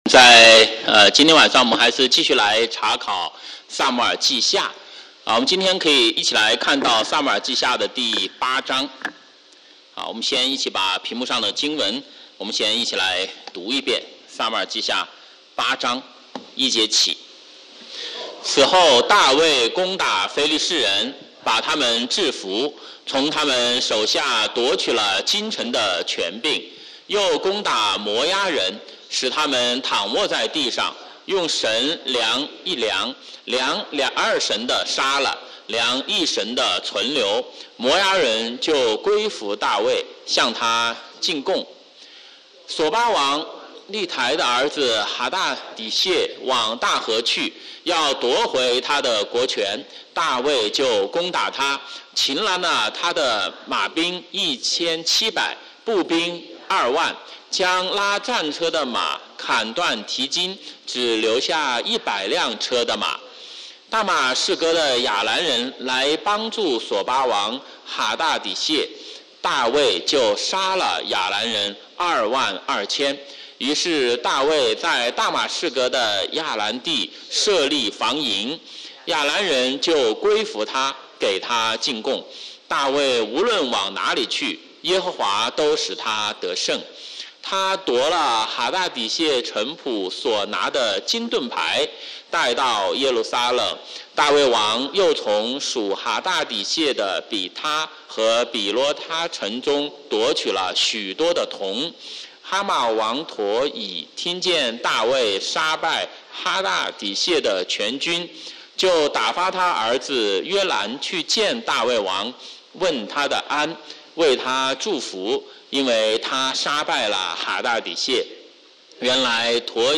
週五晚上查經講道錄音